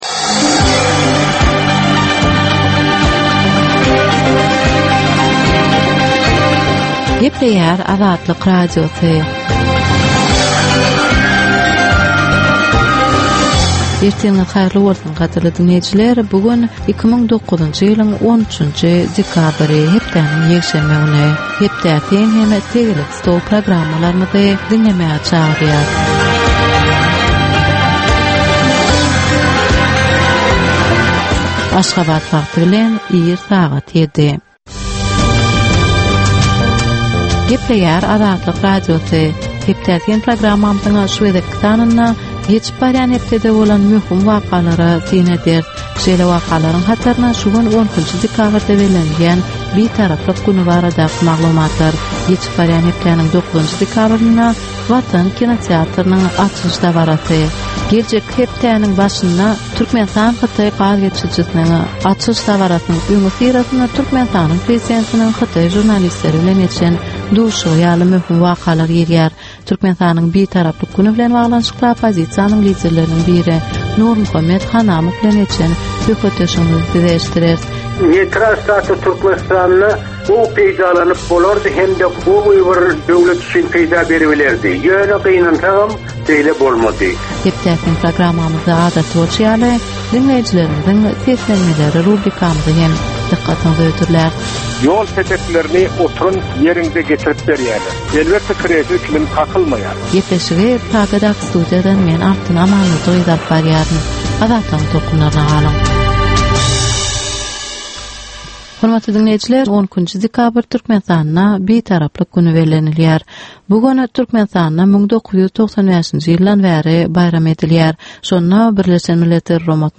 Tutuş geçen bir hepdäniň dowamynda Türkmenistanda we halkara arenasynda bolup geçen möhüm wakalara syn. 30 minutlyk bu ýörite programmanyn dowamynda hepdäniň möhüm wakalary barada gysga synlar, analizler, makalalar, reportažlar, söhbetdeşlikler we kommentariýalar berilýär.